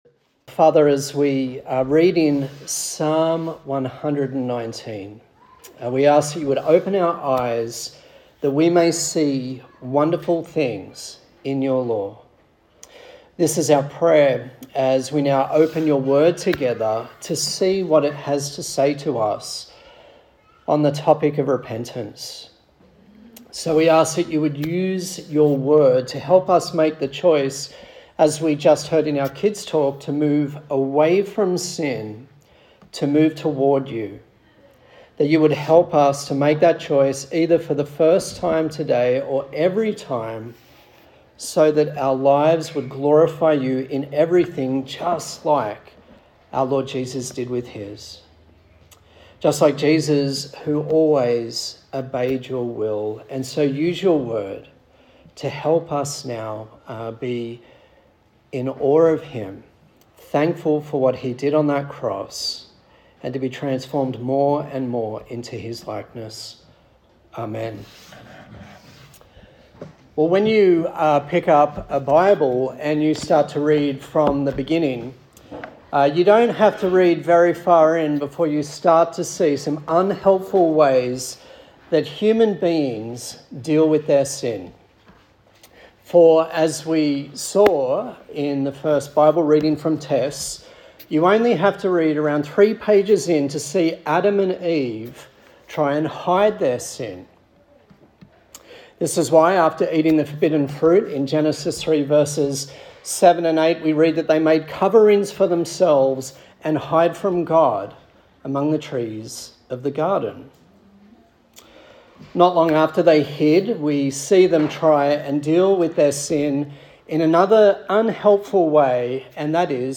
A sermon in the Kingdom Come series on the Gospel of Matthew
Kingdom Come Passage: Matthew 3:1-17 Service Type: Morning Service